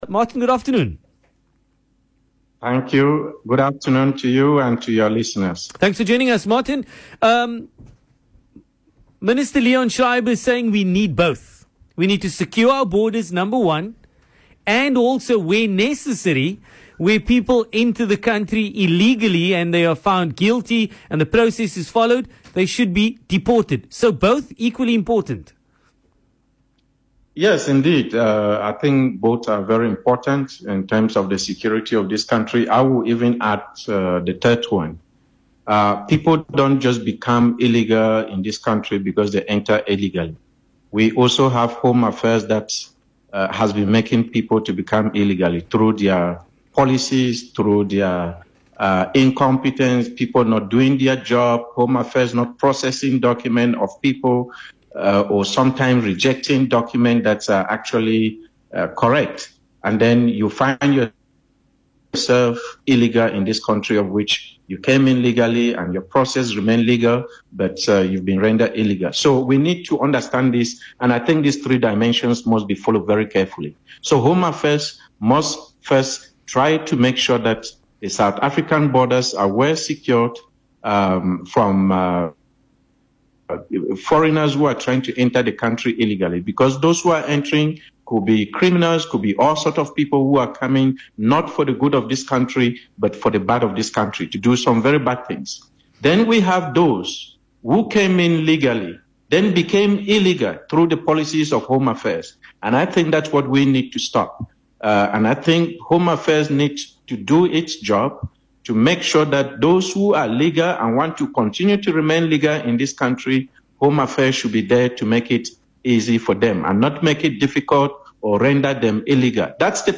an organized crime expert